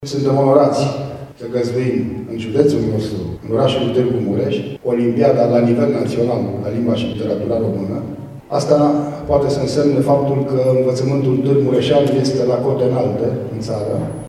Deschiderea oficială a competiţiei a avut loc aseară, în prezenţa elevilor participanţi, ai profesorilor, ai inspectorilor şcolari şi ai reprezentanţilor autorităţilor locale.
Prefectul judeţului Mureş, Lucian Goga, a declarat că, decizia organizării olimpiadei naţionale la Tîrgu-Mureş este şi o confirmare a bunului mers a învăţământului mureşean.